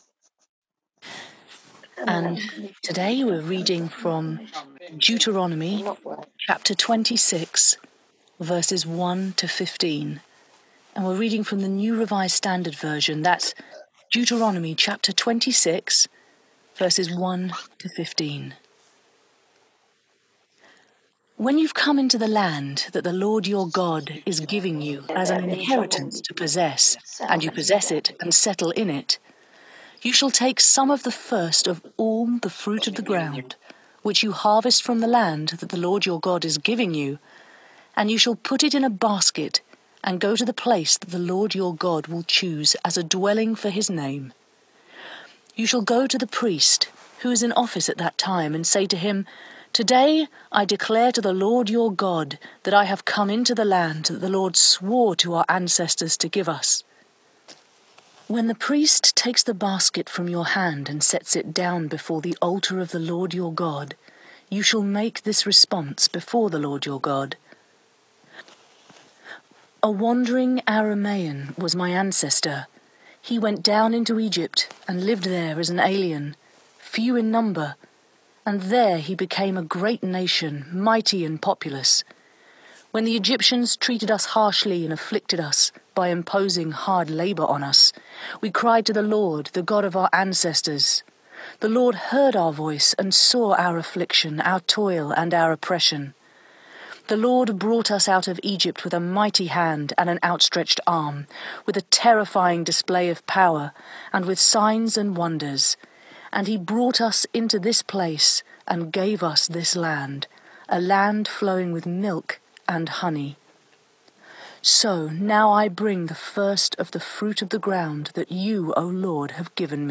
With a relaxing of the lock down rules during the Coronavirus crisis the church is able to run services again in the sanctuary, but with a limited number of worshippers.
Below is the recording of the sermon for this week.
However, not all intrusive sounds from online members were able to be separated to be removed.